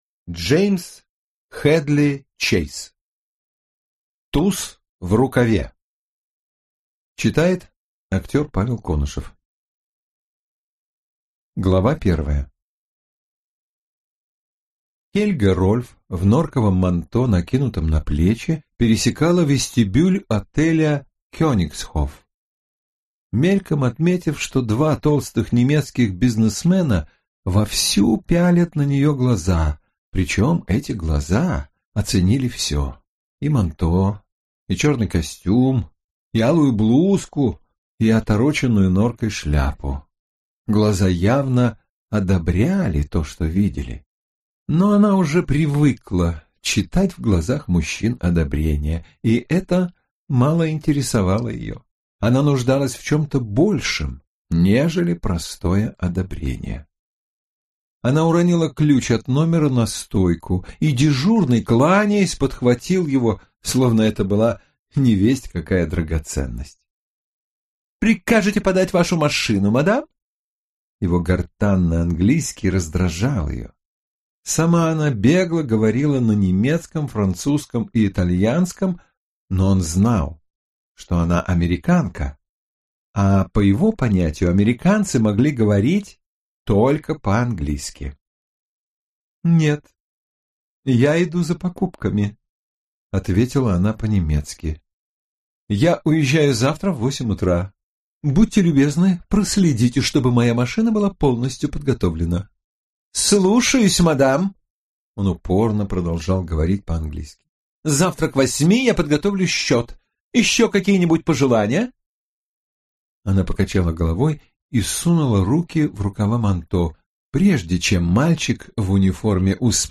Аудиокнига Туз в рукаве | Библиотека аудиокниг